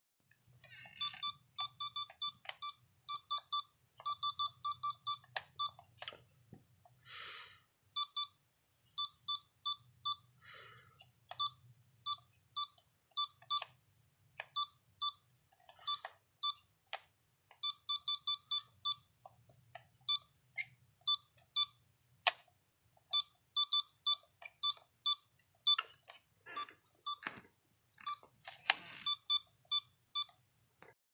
Literally Just Beeping
literally-just-beeping-67706.mp3